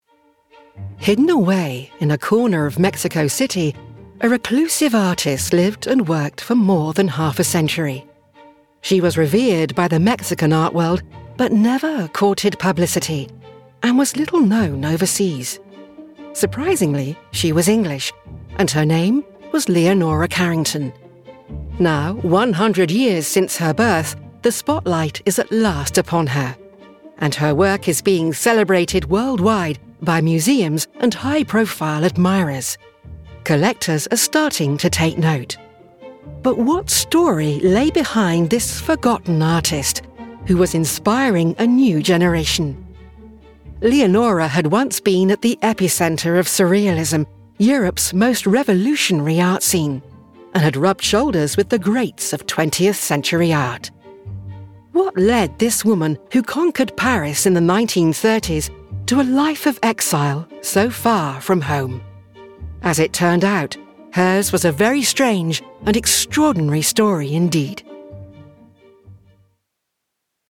Female
Assured, Engaging, Reassuring, Smooth, Warm
RP - West Country (Gloucestershire - Native) - Southern Irish - Welsh - American English -
Microphone: Sennheiser MK4 - Sennheiser MKH416